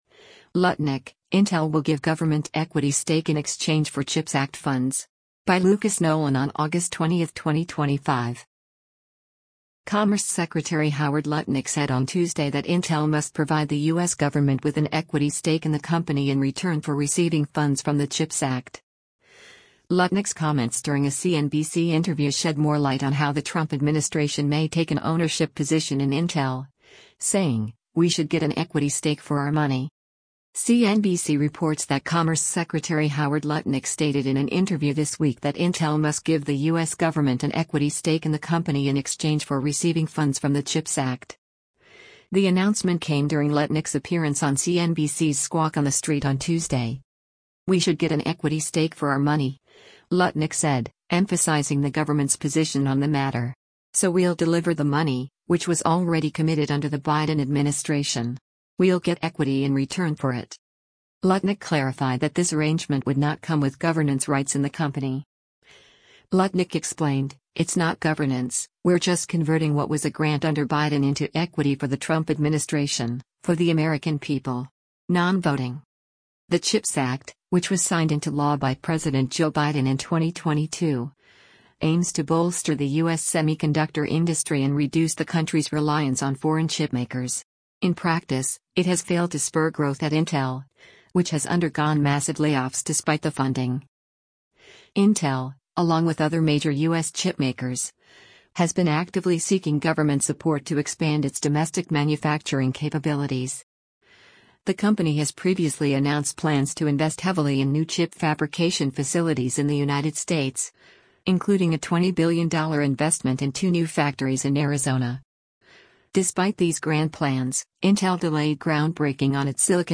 The announcement came during Lutnick’s appearance on CNBC’s Squawk on the Street on Tuesday.